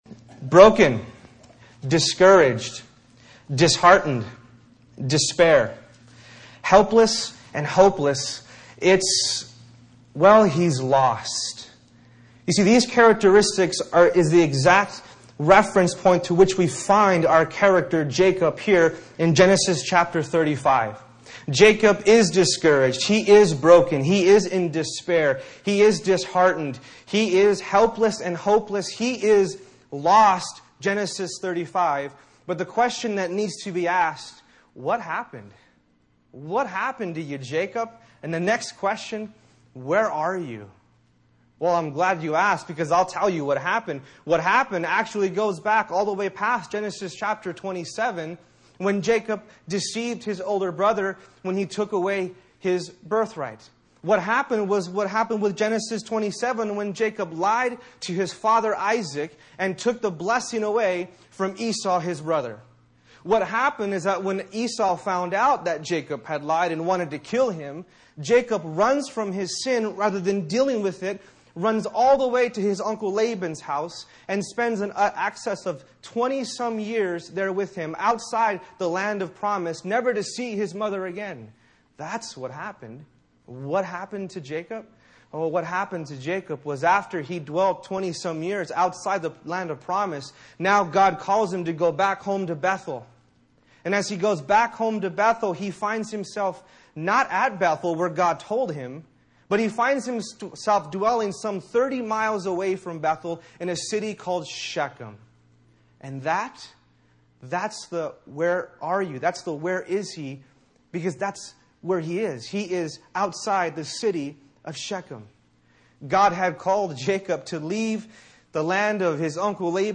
Holiday Message